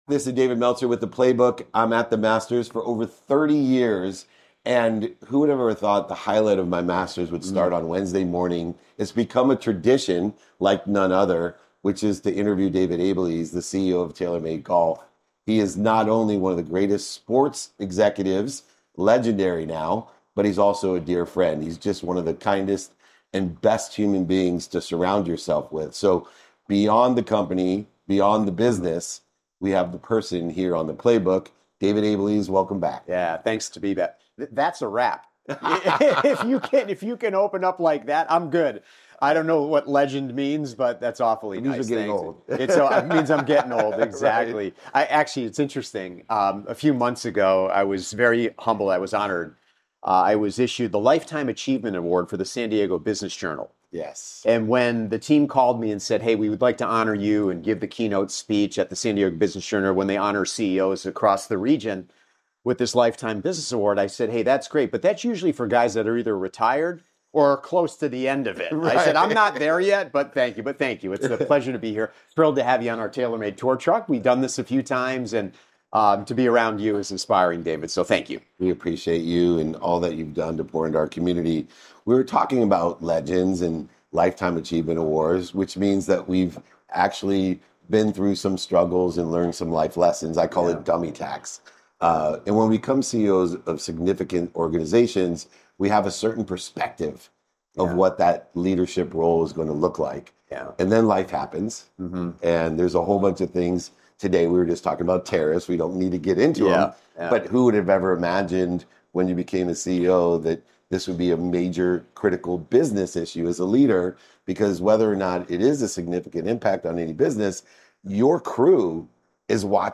from the tour truck at Augusta National.